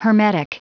Prononciation du mot hermetic en anglais (fichier audio)
Prononciation du mot : hermetic